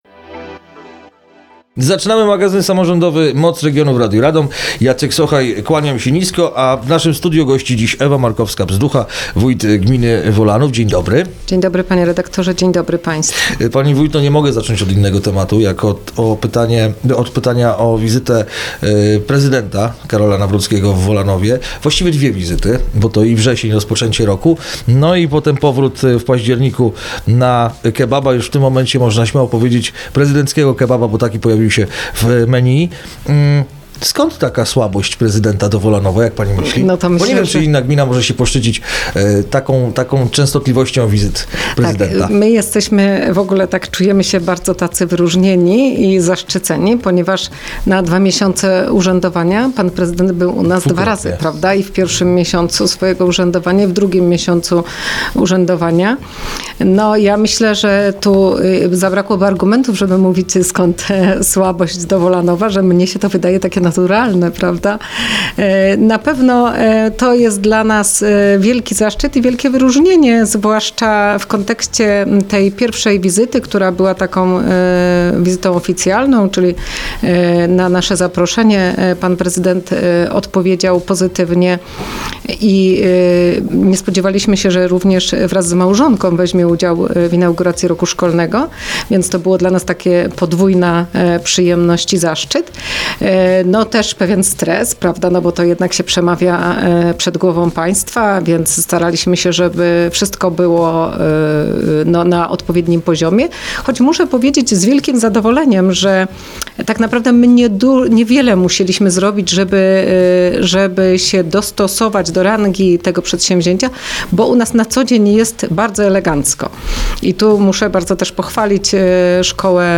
Rozmowa także jest dostępna na facebookowym profilu Radia Radom: